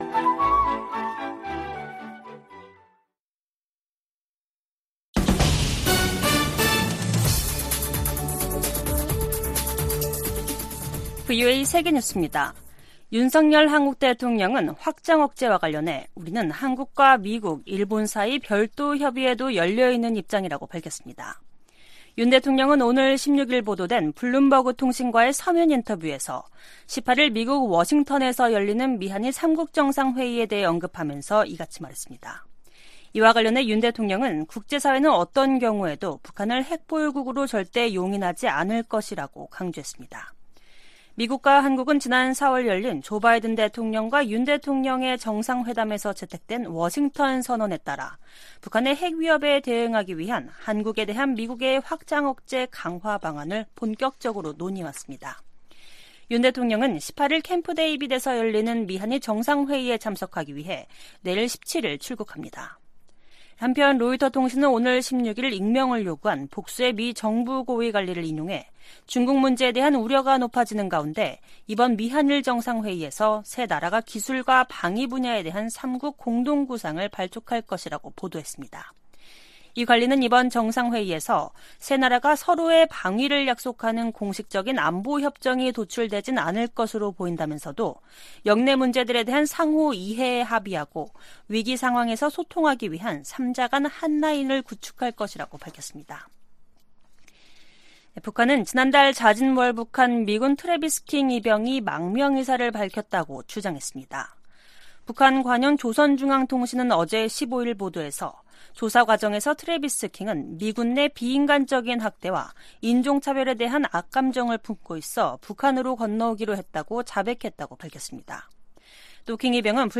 VOA 한국어 간판 뉴스 프로그램 '뉴스 투데이', 2023년 8월 16일 3부 방송입니다. 토니 블링컨 미 국무장관이 오는 미한일 정상회의와 관련해 3국 협력의 중요성을 강조했습니다. 윤석열 한국 대통령은 미한일 정상회의를 앞두고 확장억제와 관련해 미한일 사이 별도의 협의도 열려 있다고 밝혔습니다. 미 국방부는 최근 김정은 북한 국무위원장이 ‘전쟁 준비 태세를 갖추라’고 지시한 것과 관련해 한국, 일본에 대한 미국의 안보 공약은 분명하다고 강조했습니다.